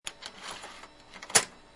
VmTicketInsert.mp3